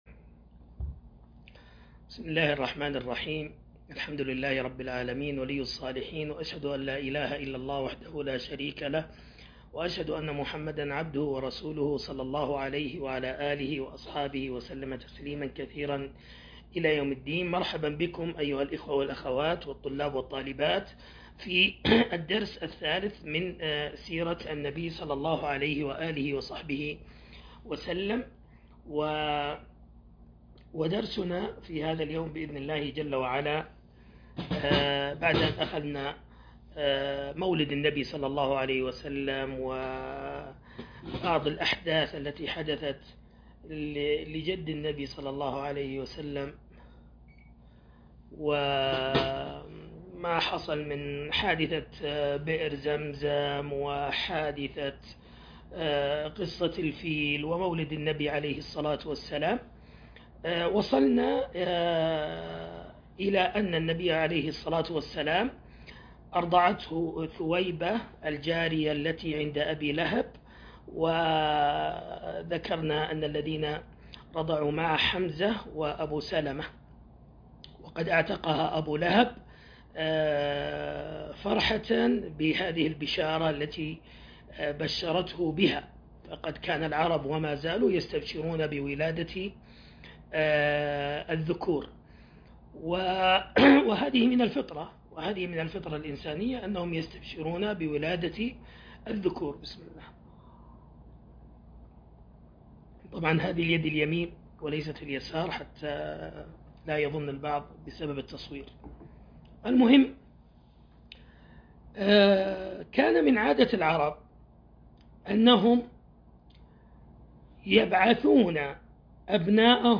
الدرس الثالث فصول من السيرة النبوية